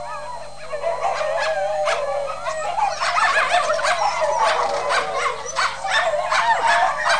dogs20.mp3